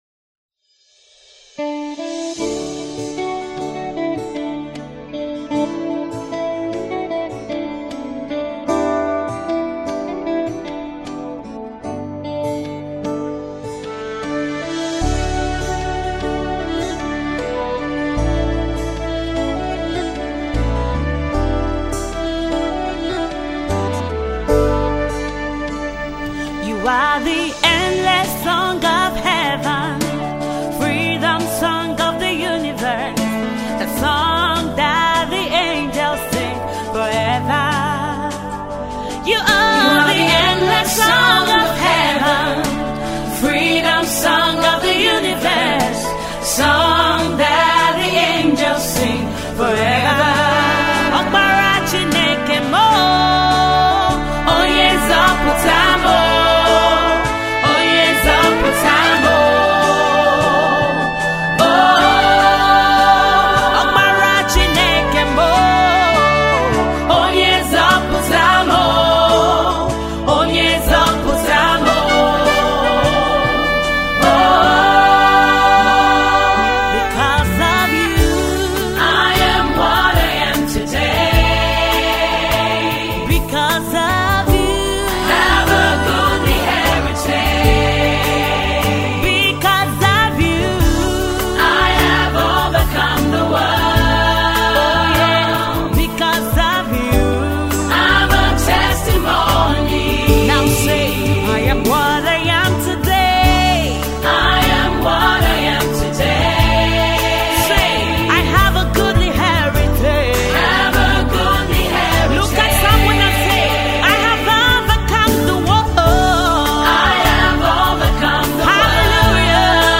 electrifying gospel song